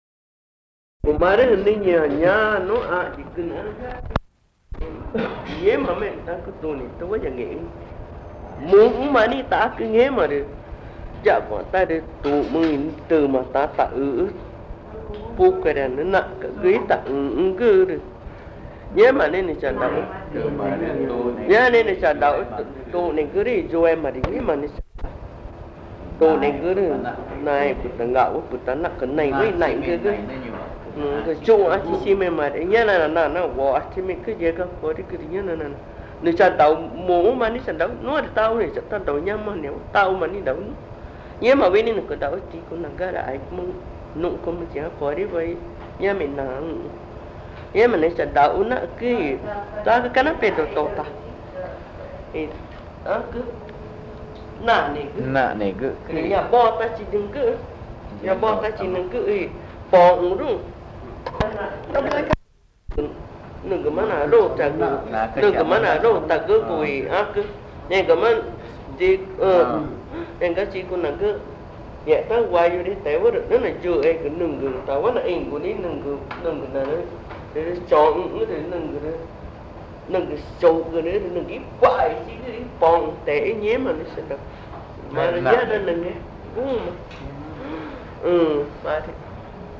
Belém do Pará, Pará (Brazil)